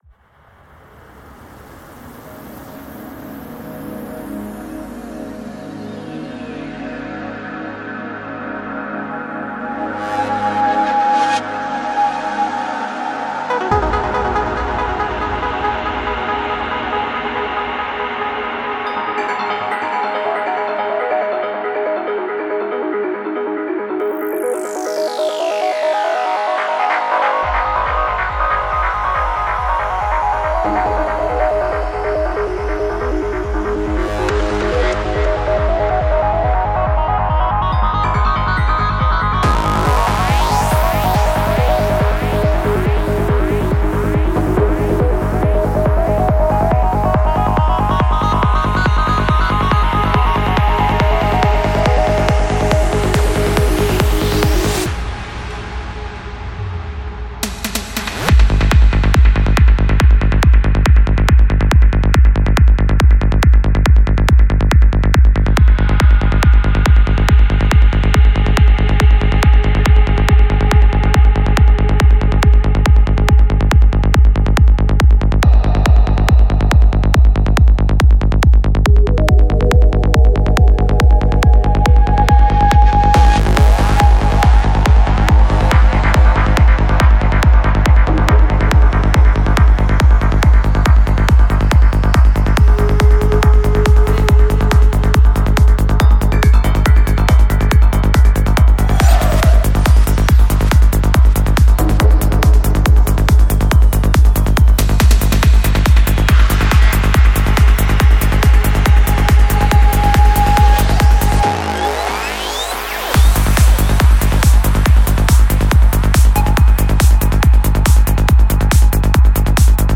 Жанр: Psy-Trance